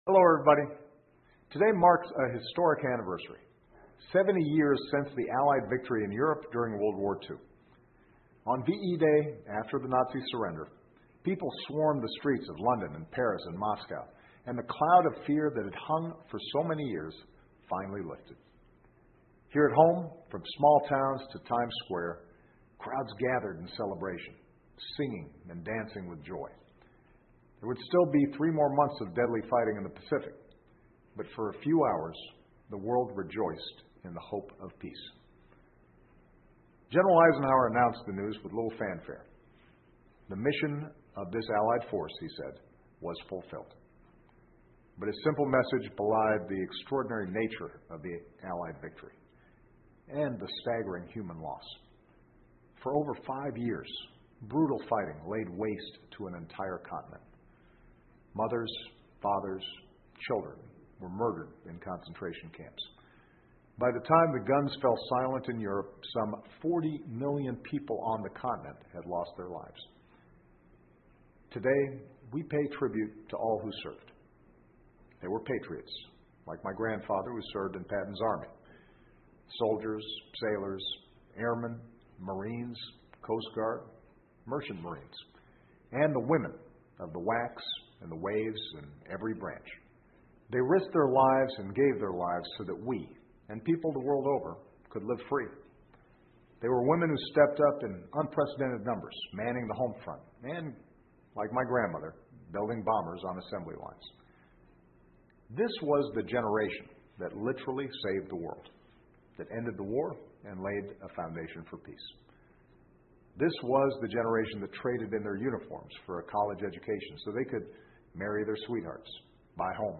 奥巴马每周电视讲话：总统发表讲话纪念二战欧洲战场胜利70周年 听力文件下载—在线英语听力室